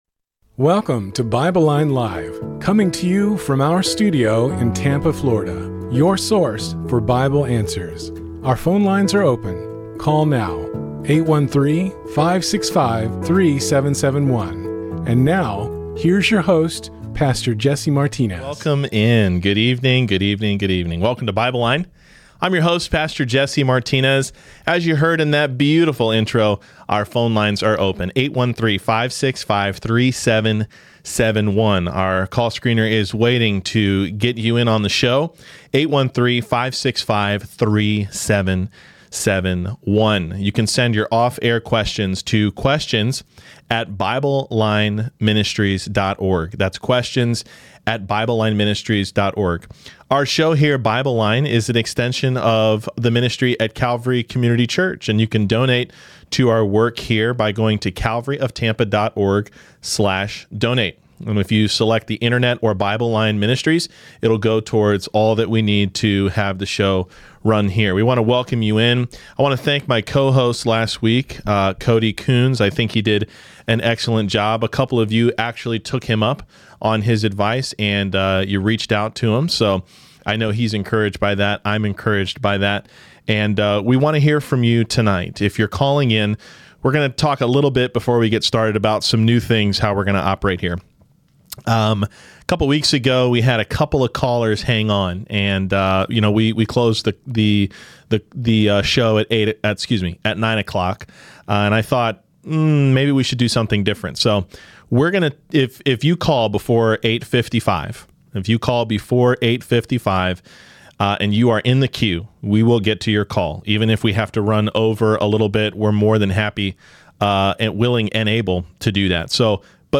BibleLine LIVE QNA Replay | GBN, 1 John 1:9 Confession, Pastor Arguing, Pre-Trib Rapture and more!